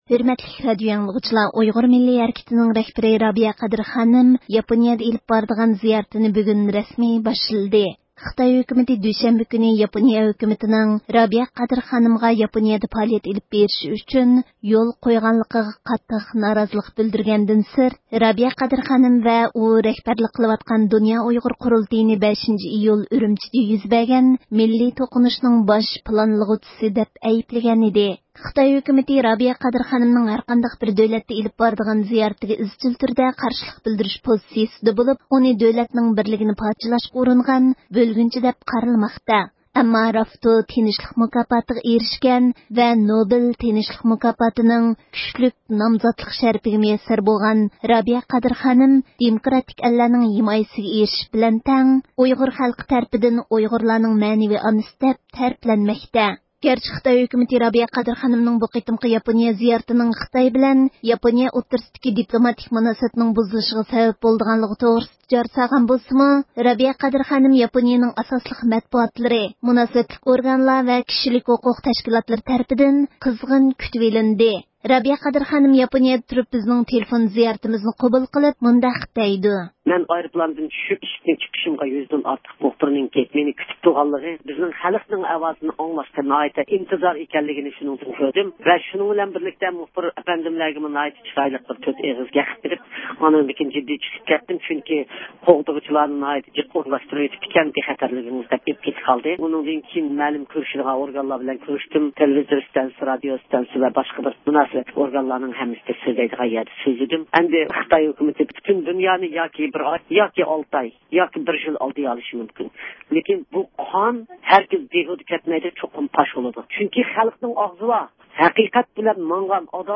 رابىيە قادىر خانىم، ياپونىيىدە تۇرۇپ بىزنىڭ تېلېفۇن زىيارىتىمىزنى قوبۇل قىلىپ، ياپونىيىدىكى ئۆز پائالىيىتى توغرىسىدا توختالدى. شۇنداقلا، زىيارىتىمىزنى قوبۇل قىلغان ياپونىيىدە ياشاۋاتقان ئۇيغۇرلار ۋە ئوقۇغۇچىلار، رابىيە قادىر خانىمنىڭ ياپونىيە زىيارىتىنىڭ ئەھمىيىتى ھەققىدە توختالدى.